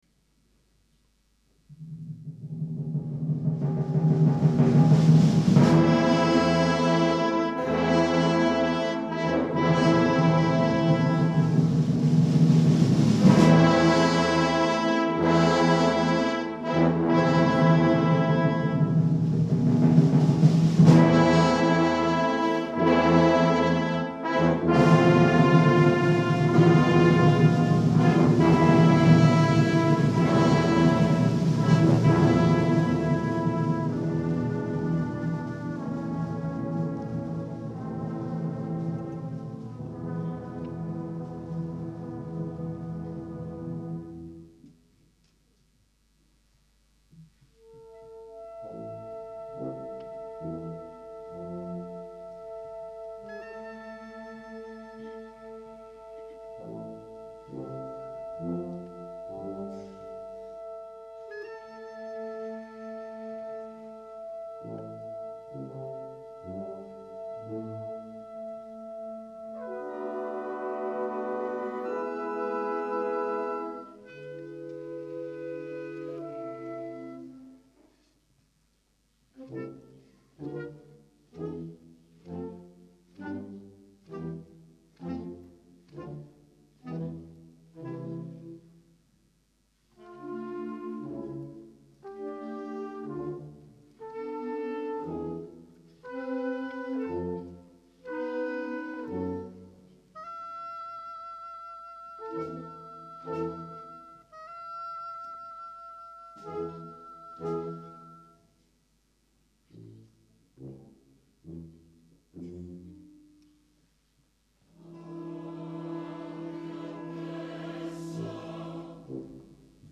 I Brani Musicali sono stati registrati al Teatro "A. Bonci" di Cesena il 18 Febbraio 2001 durante il
CONCERTO LIRICO
CORALE BANDISTICO
Il Coro Lirico Città di Cesena
Banda "Città di Cesena"